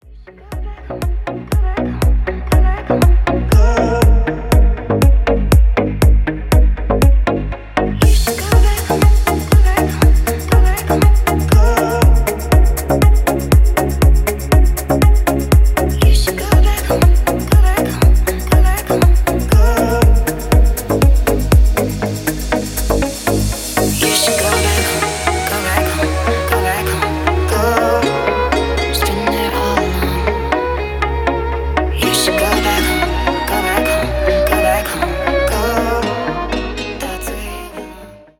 • Качество: 320, Stereo
deep house
атмосферные
Electronic
спокойные
скрипка
красивый женский голос